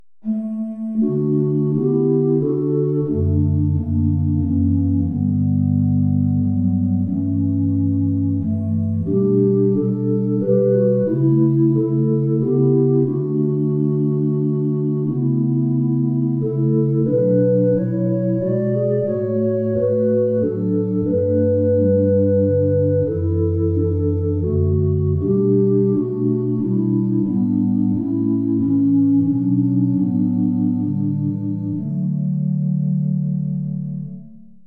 kör
i_vinden_kor.mp3